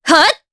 Aselica-Vox_Attack1_jp_b.wav